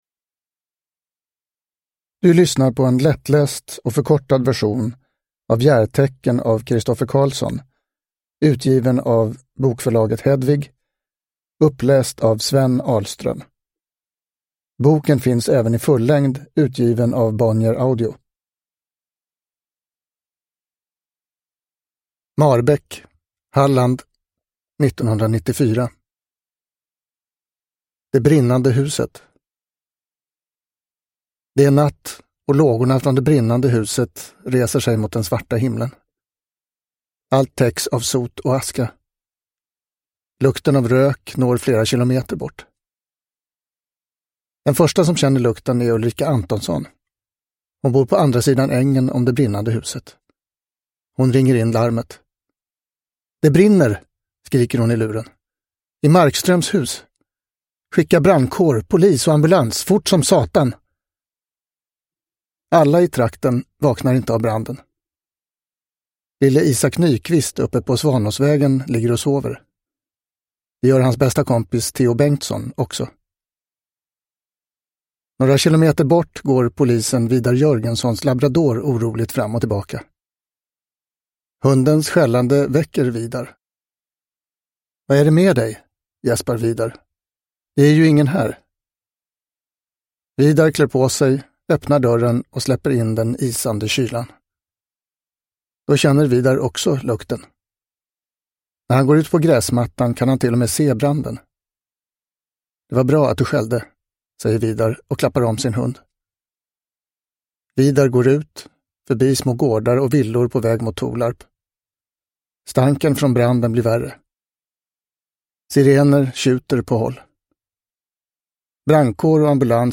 Järtecken (lättläst) – Ljudbok